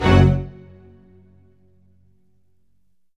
ORCHHIT F07R.wav